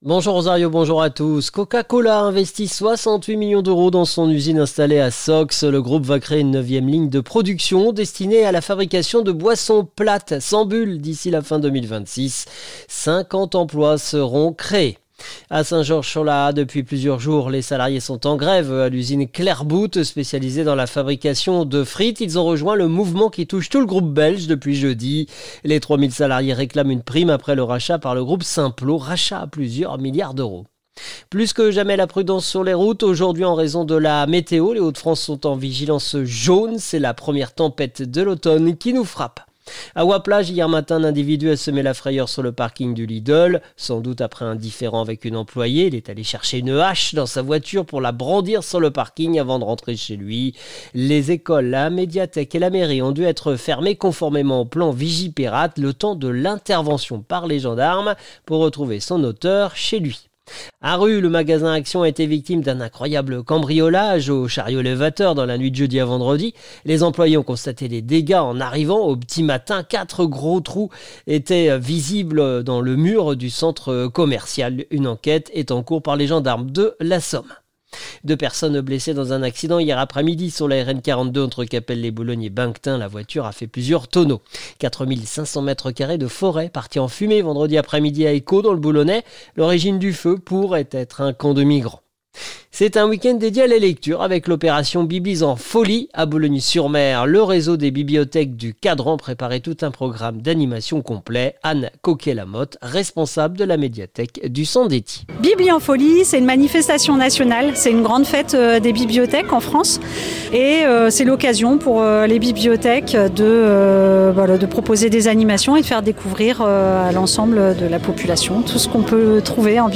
Le journal de ce samedi 4 octobre 2025